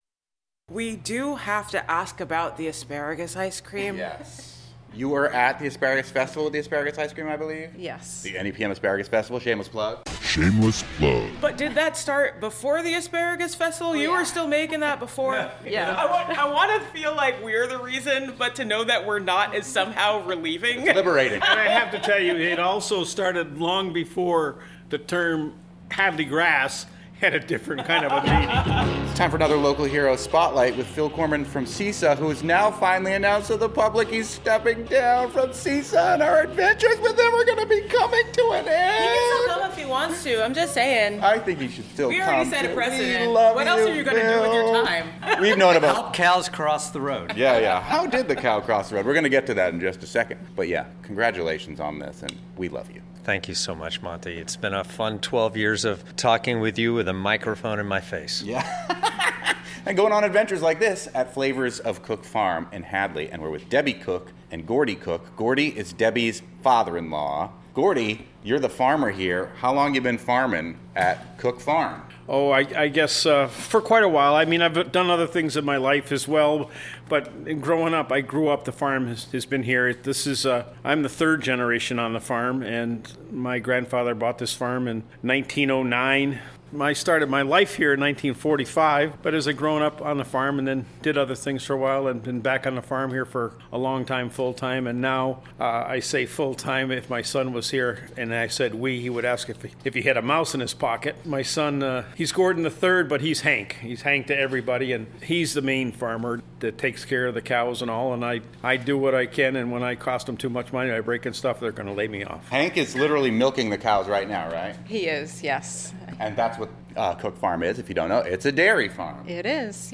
NEPM "Fabulous 413" interviews